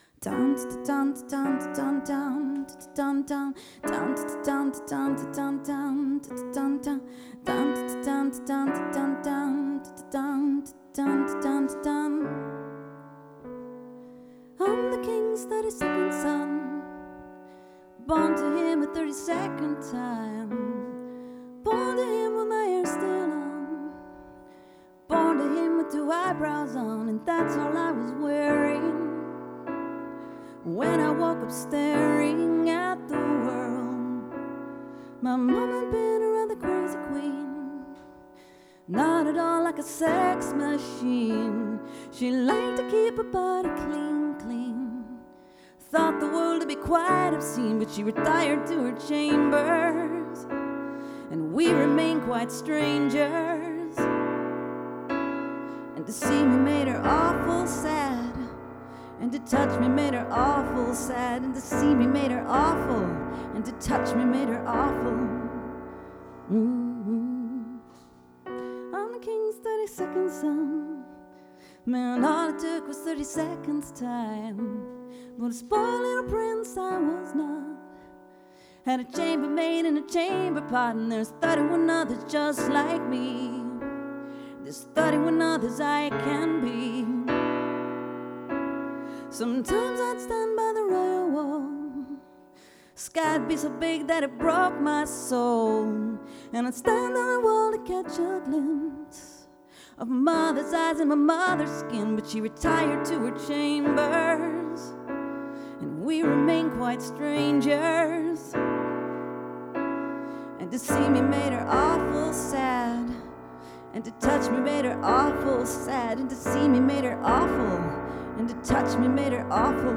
Recorded live August 31, 2016 at Martyrs', Chicago, IL